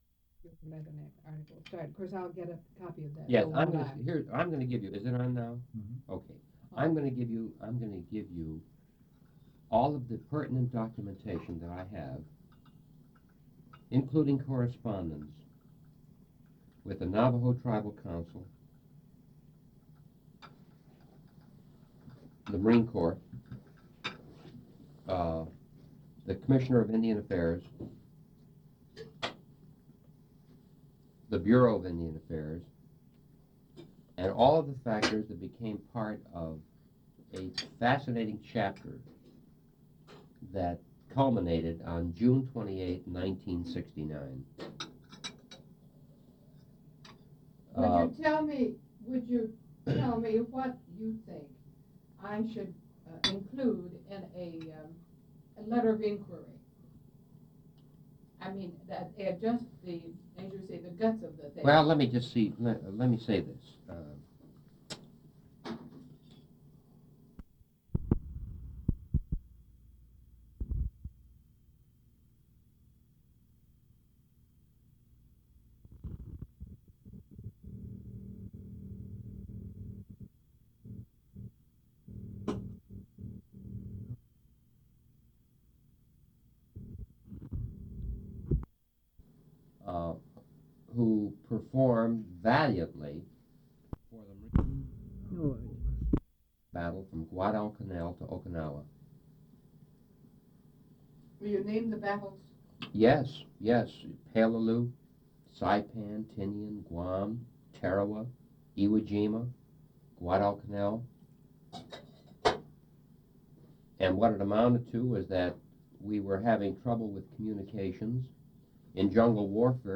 Interviews
Audio/mp3 Original Format: Audio cassette tape Resource Identifier